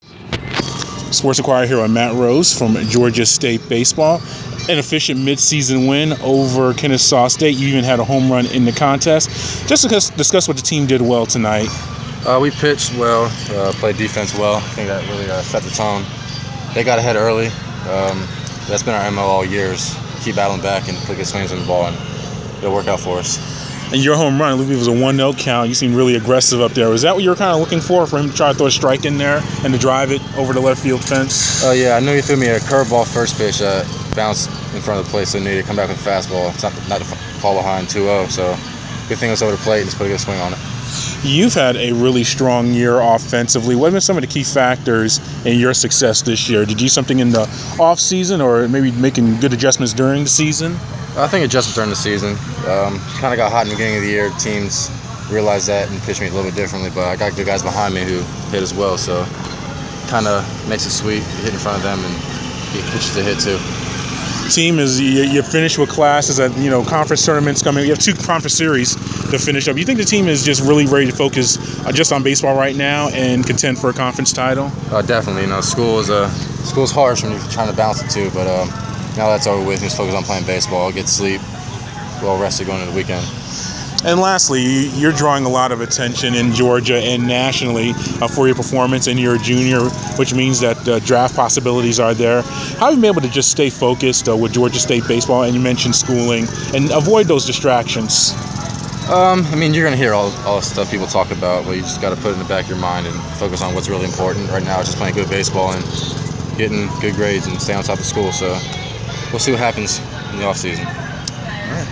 Inside the Inquirer: Postgame interview